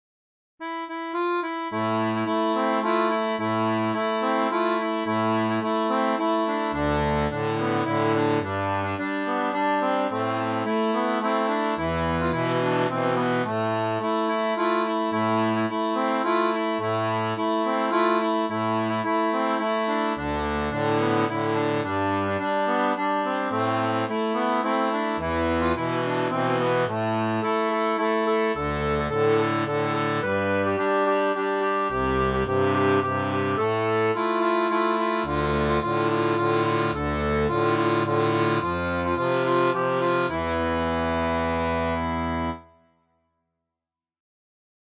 • Une tablature pour diato 2 rangs transposée en La
Chanson française